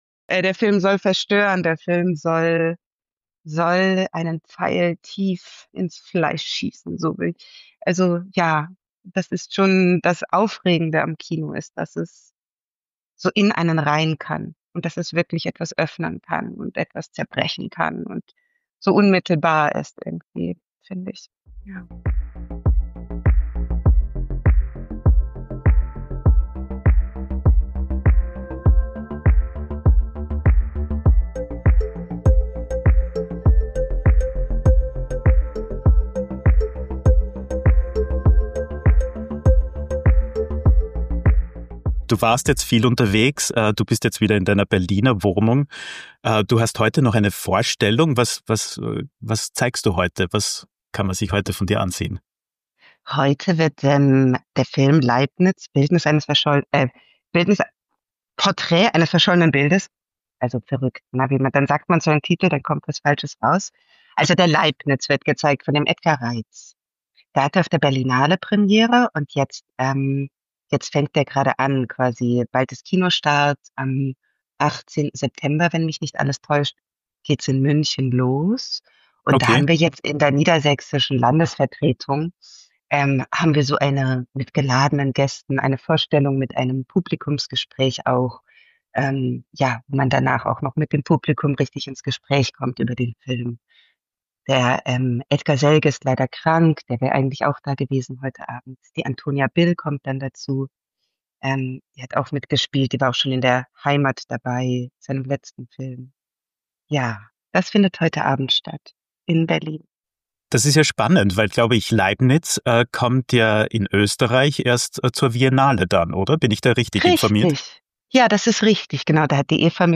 Ein Gespräch über Kunst, Haltung und die Suche nach Selbstbestimmung – im Kino wie im Leben.